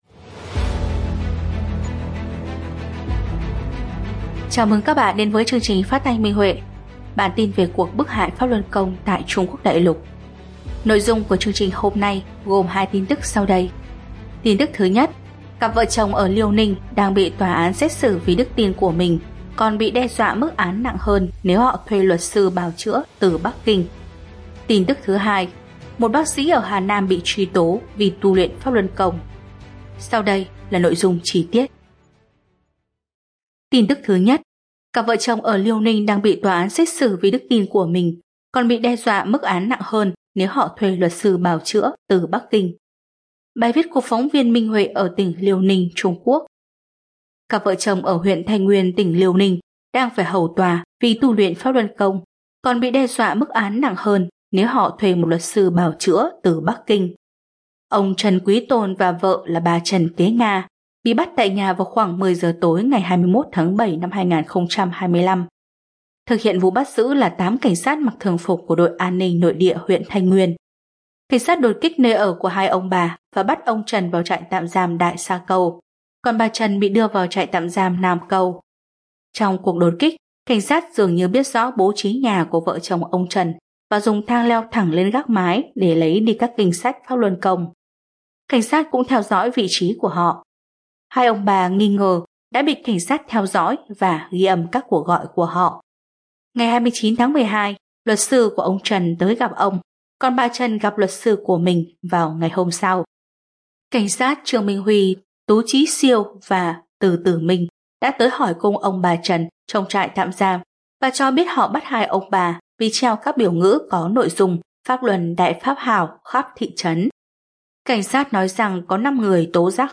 Chương trình phát thanh số 269: Tin tức Pháp Luân Đại Pháp tại Đại Lục – Ngày 21/12/2025